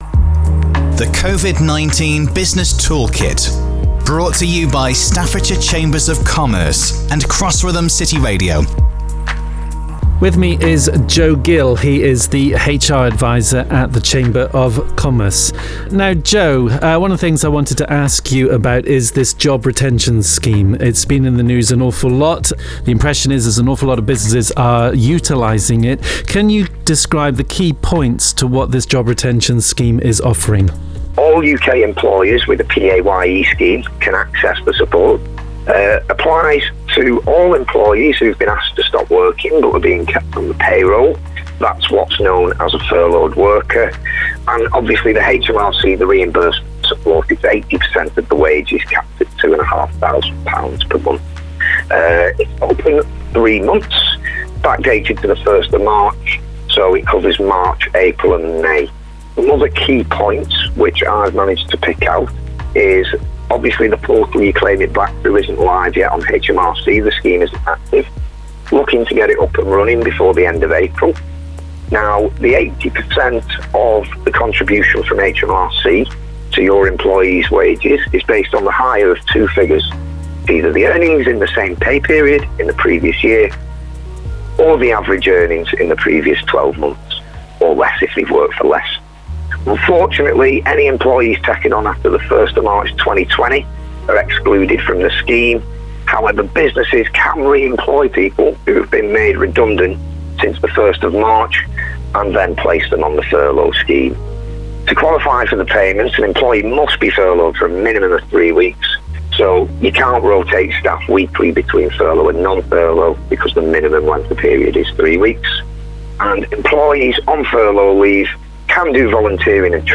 Each podcast features interviews with specialists from Staffordshire Chambers of Commerce and covers topics that businesses may find informative, such as: Funding advicejob retention & self employed schemesonline mentoringbusiness crime support and more...